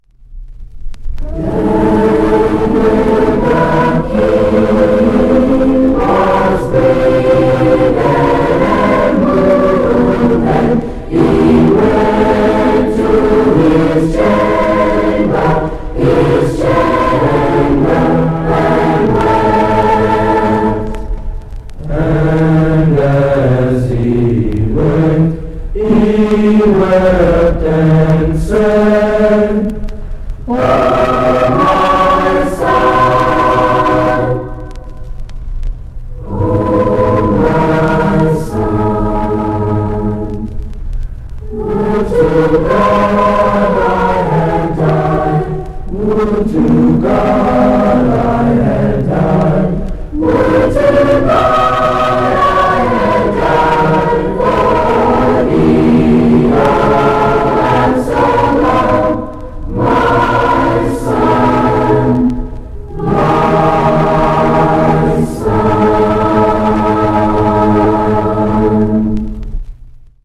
J.W. Cannon Jr. High School Chorus